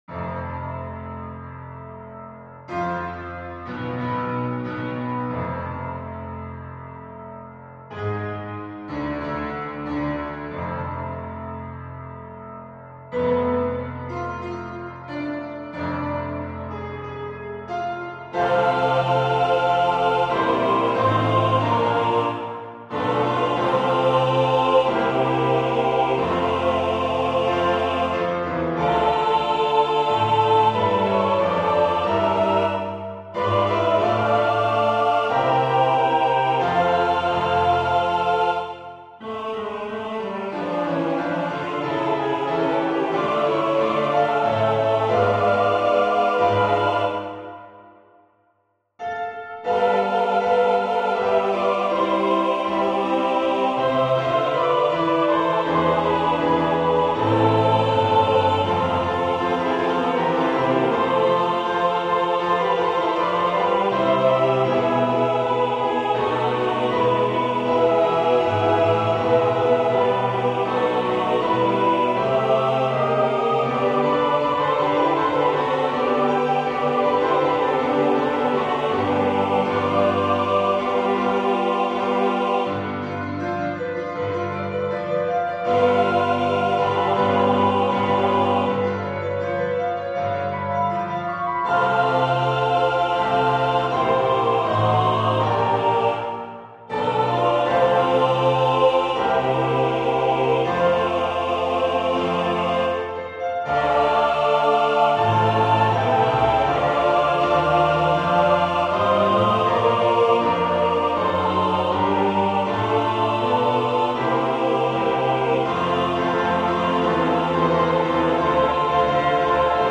FF:HV_15b Collegium musicum - mužský sbor